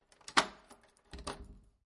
锁好门
描述：锁住房子的室外
Tag: 关闭 钥匙